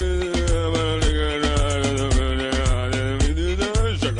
Worms speechbanks
brilliant.wav